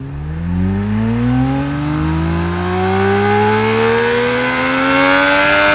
rr3-assets/files/.depot/audio/Vehicles/f1_02/f1_02_accel.wav
f1_02_accel.wav